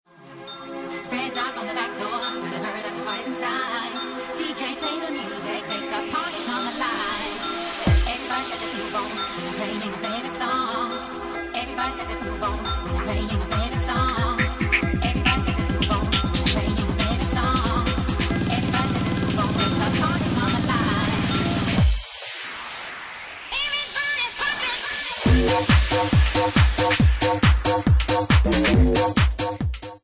Bassline House at 138 bpm